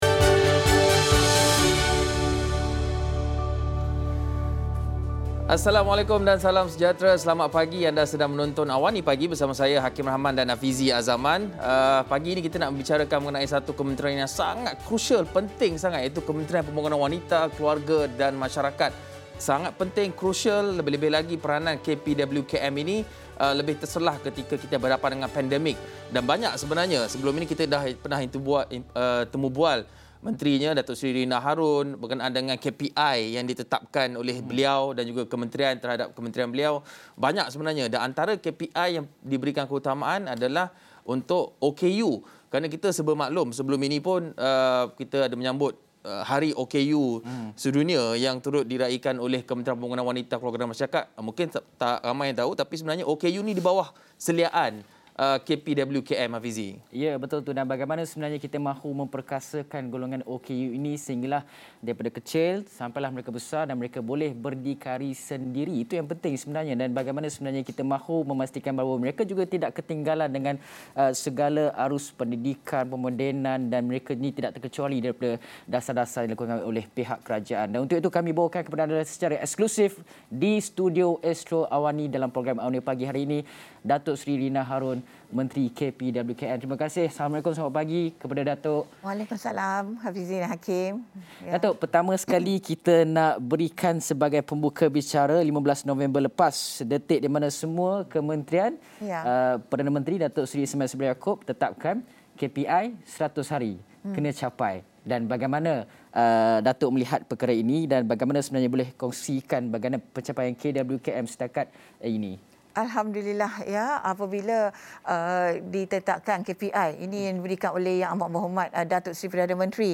Ikuti temubual bersama pemimpin wanita ini dalam AWANI Pagi.